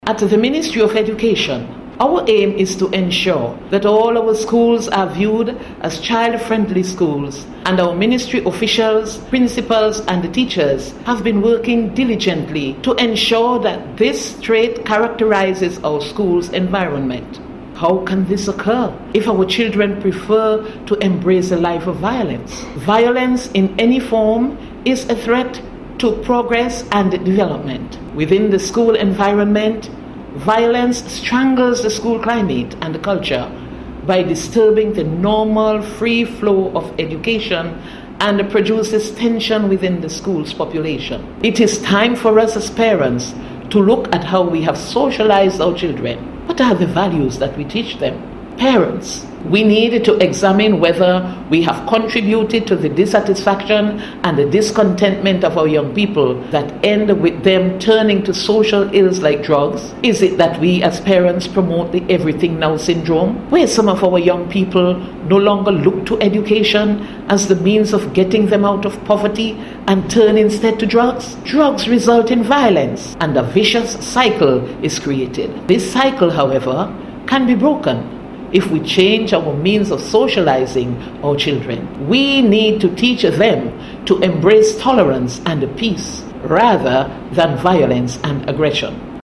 Minister Miguel spoke on the issue, as she delivered a radio address to mark the start of Child Month 2014, which is being observed under the theme: Tolerance today for a violence free world tomorrow.